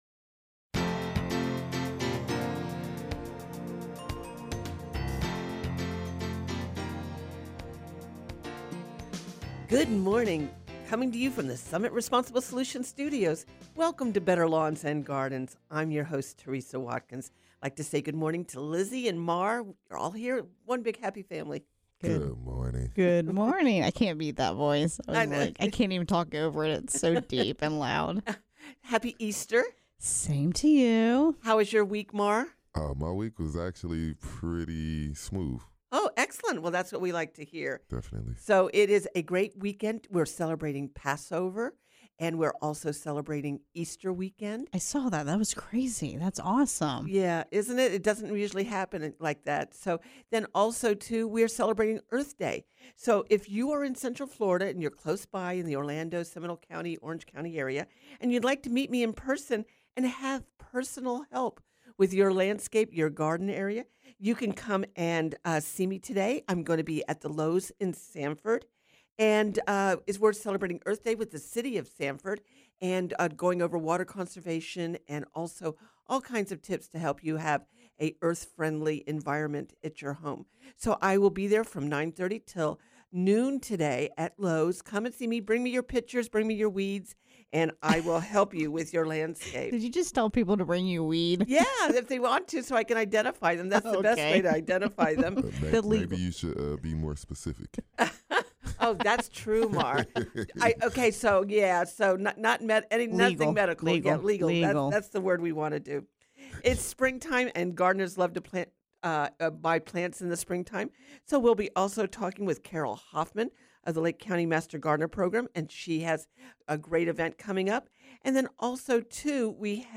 Gardening calls and texts include plants for wet clay soils, powdery mildew on crape myrtles, will I get more mango blooms this year, bird watching, citrus, magnolia tree fertilizer, growing lilies, and more.